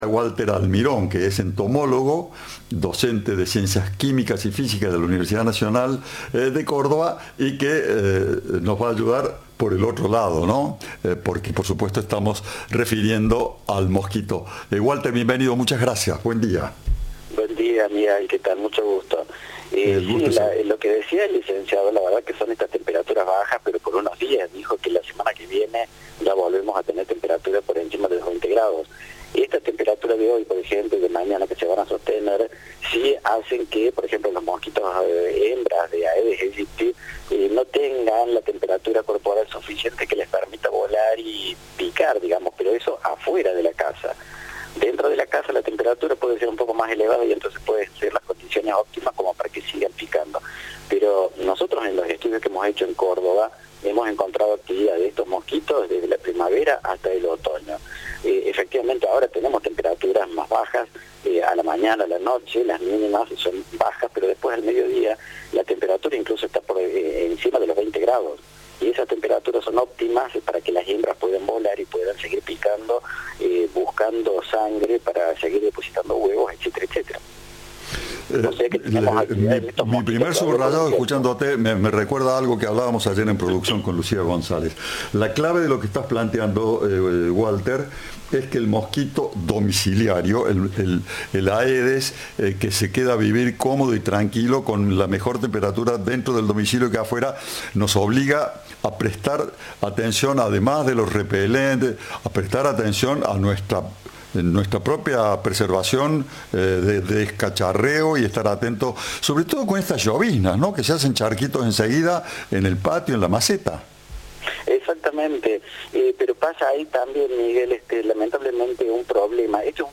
Especialistas hablaron con Cadena 3 para explicar cómo influye la temperatura en el comportamiento y reproducción del Aedes Aegypti.